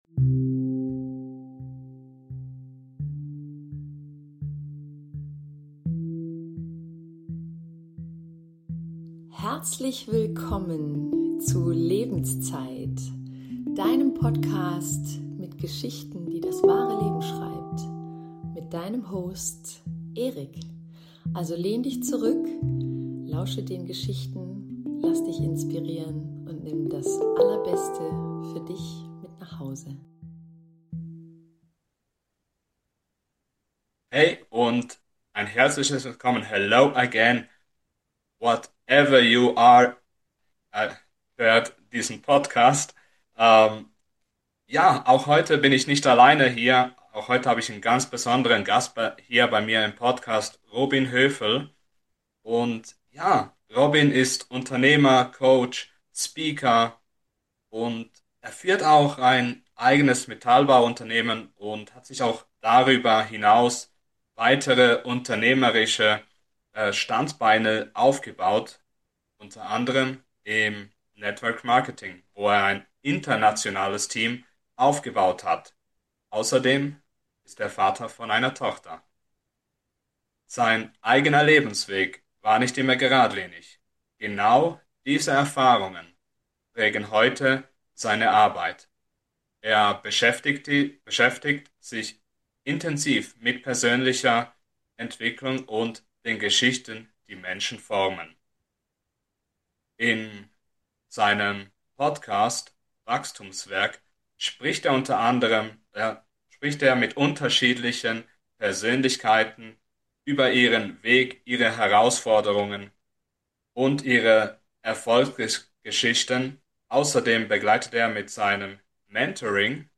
Herzlich Willkommen zur nächsten Interview Folge